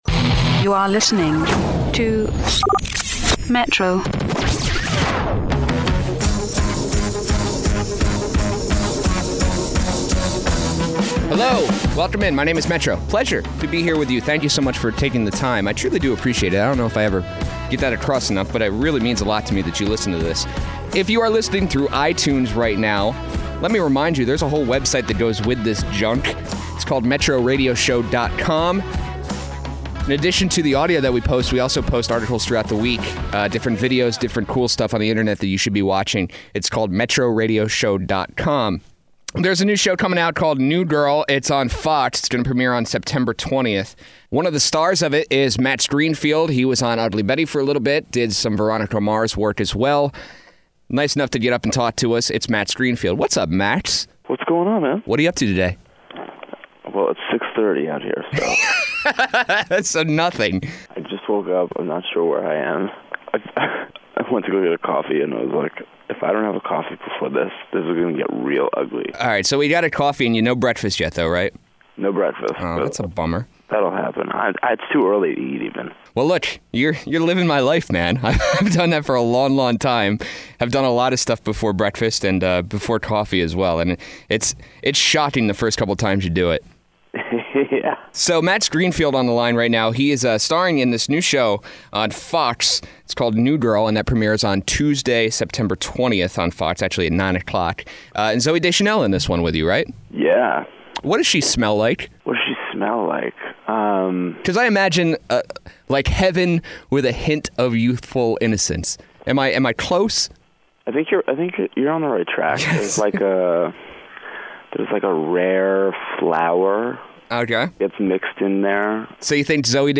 Most importantly though, Max Greenfield was nice enought to get out of bed to talk about his new show.